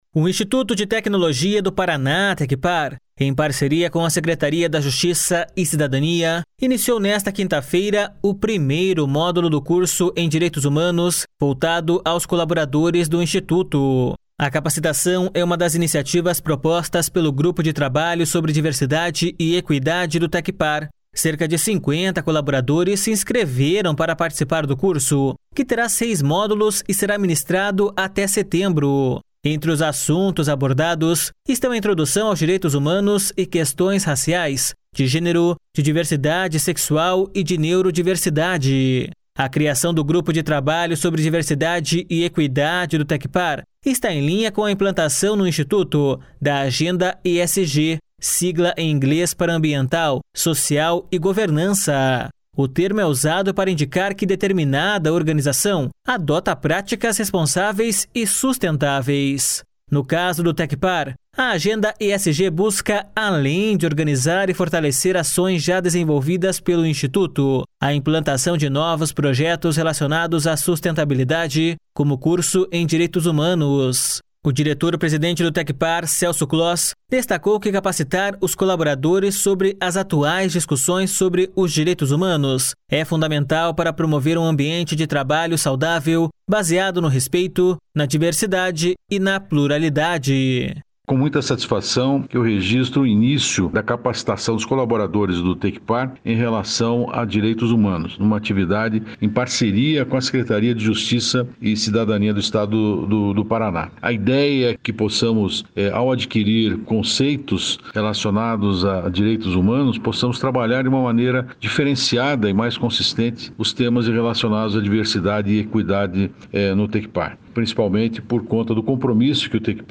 O diretor-presidente do Tecpar, Celso Kloss, destacou que capacitar os colaboradores sobre as atuais discussões sobre os direitos humanos é fundamental para promover um ambiente de trabalho saudável baseado no respeito, na diversidade e na pluralidade.// SONORA CELSO KLOSS.//
De acordo com o secretário da Justiça e Cidadania, Santin Roveda, esta é mais uma parceria de sucesso com o objetivo de promover os Direitos Humanos.// SONORA SANTIN ROVEDA.//